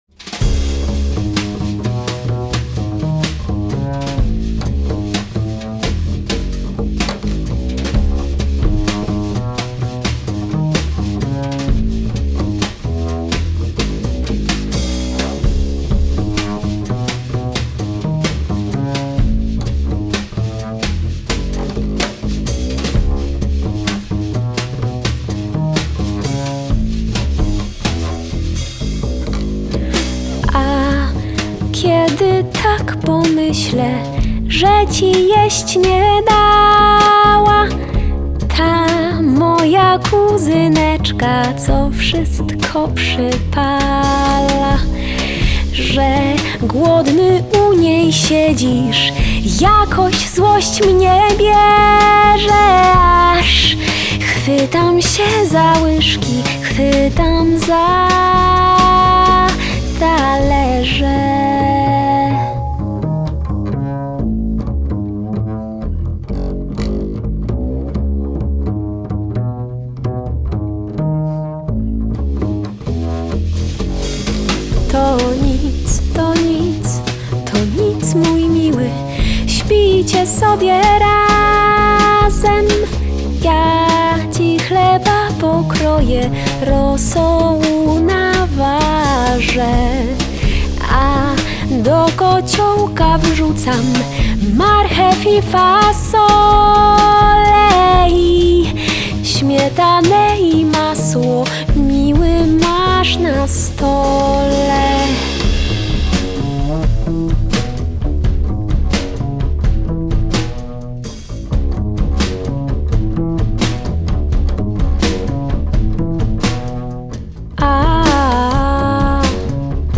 Genre: Vocal Jazz, Avant-Garde, Folk
klarnet, drumla, śpiew (clarinet, jaws harp, vocal)
kontrabas (double bass)